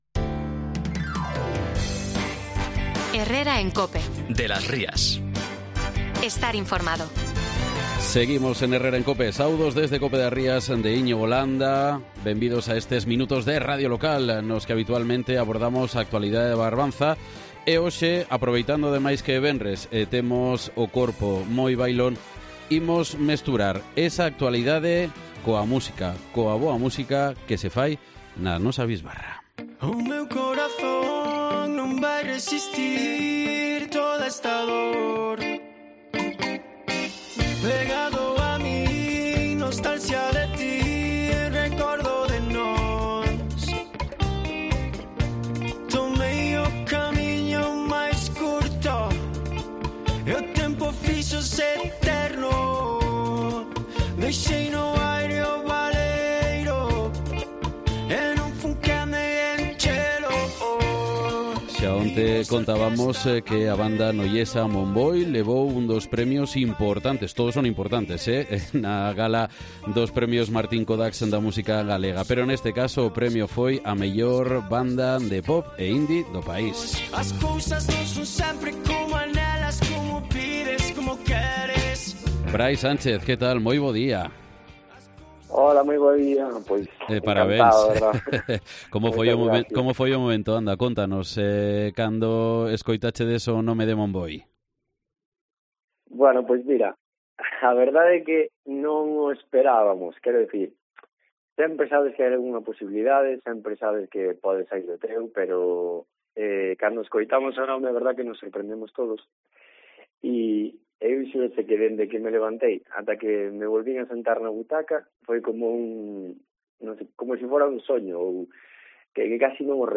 guitarra e voz da banda Momboi